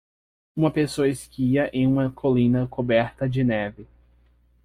Pronounced as (IPA) /koˈbɛʁ.tɐ/